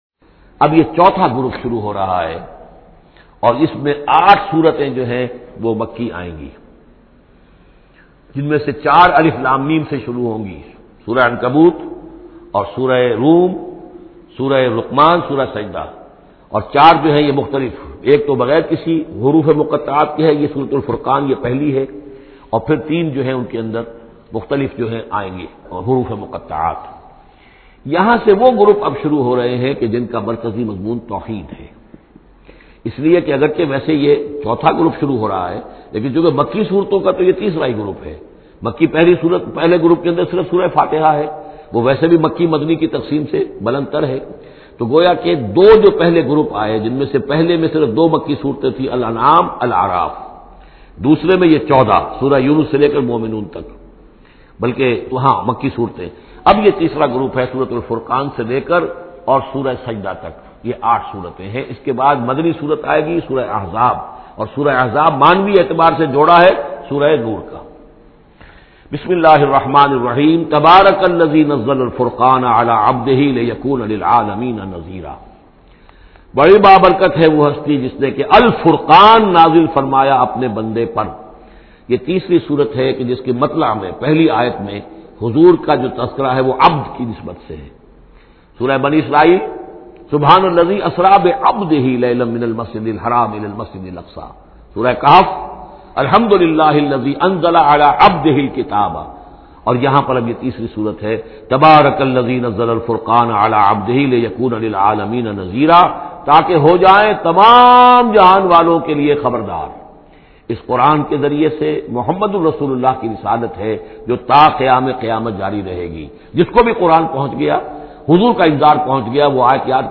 Surah Al Furqan is 25th chapter of Holy Quran. Listen online and download tafseer of Surah Al Furqan in the beautiful voice of Dr Israr Ahmed.